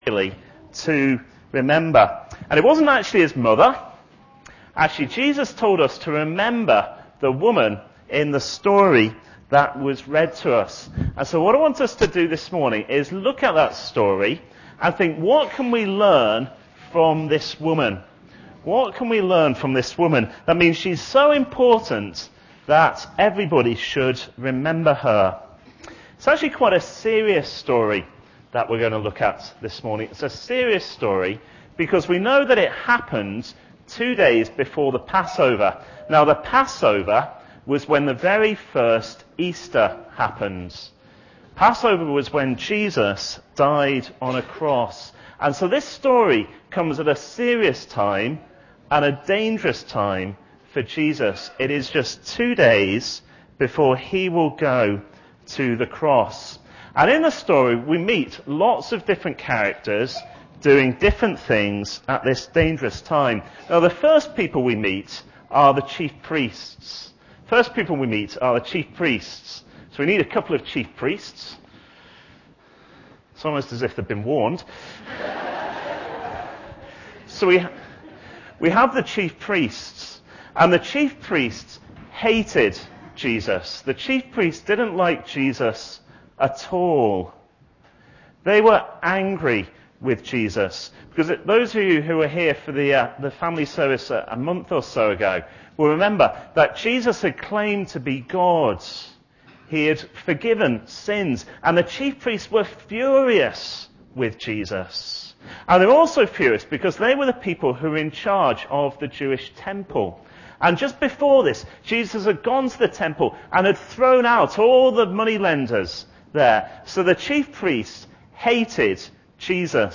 Mother's Day Guest Service - A Memorable Woman (Mark 14:1-11) Recorded at Woodstock Road Baptist Church on 02 March 2008.